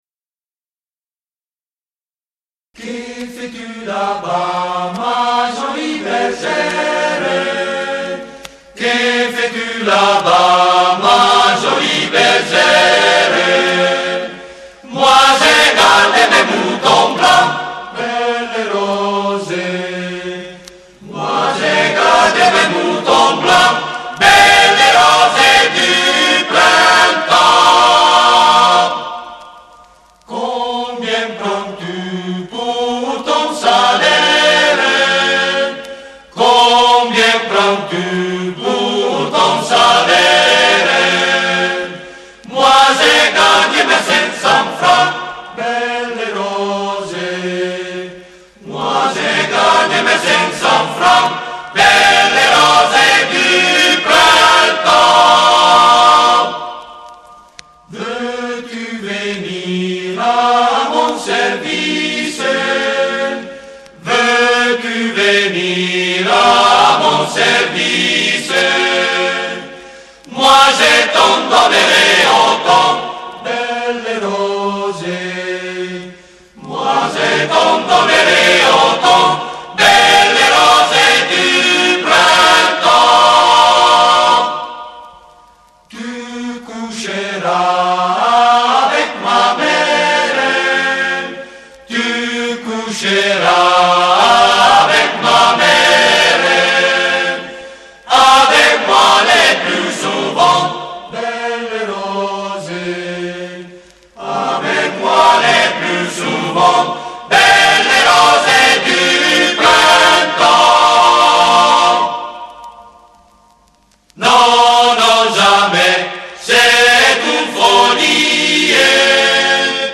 LA SU PER LE MONTAGNE - CORO DELLA S.A.T. VOL. 6
3) QUE FAIS-TU LA-BAS (Belle rose du printemps) - (Piemonte, Val d'Aosta) - armonizzazione Antonio Pedrotti Notissimo antico canto valdostano di derivazione trovadorica. Il terna della pastorella è comunissimo in tutta la poesia dell'epoca.
Esistono diverse versioni melodiche.